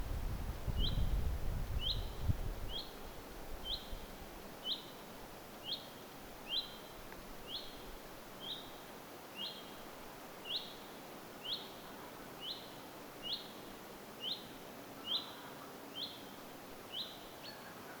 peipon hyit-ääntelyä
peipon_hyit-aantelya.mp3